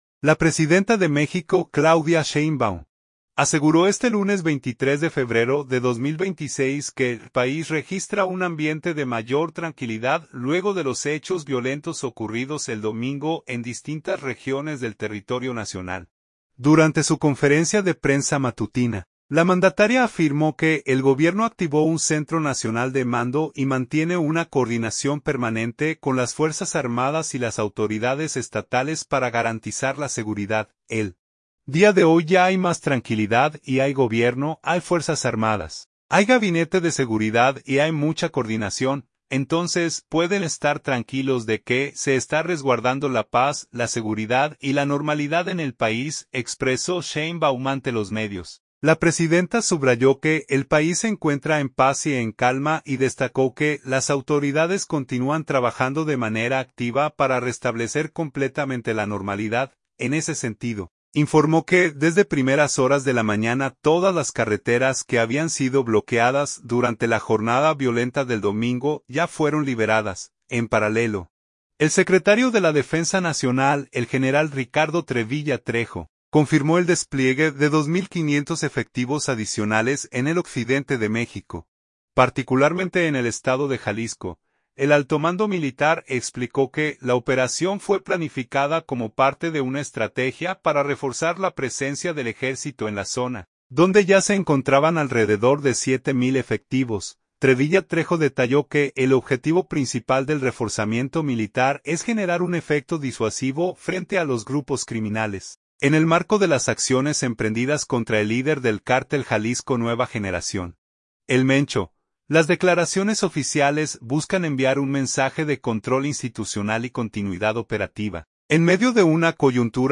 Durante su conferencia de prensa matutina, la mandataria afirmó que el Gobierno activó un centro nacional de mando y mantiene una coordinación permanente con las Fuerzas Armadas y las autoridades estatales para garantizar la seguridad.